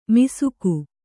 ♪ misuku